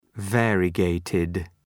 Προφορά
{‘veərıə,geıtıd}